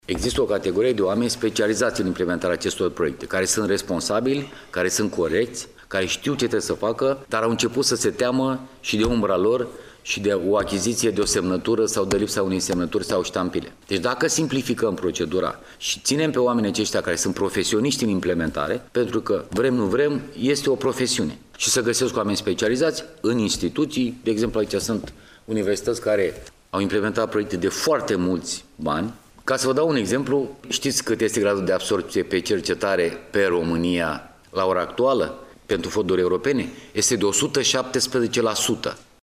Referindu-se la specialiştii care aplică pentru proiectele europene, ministrul a subliniat că procedurile în domeniu trebuie mult simplificate, deoarece România este singura ţară din Uniune care are un grad de absorbţie pe cercetare de 117%: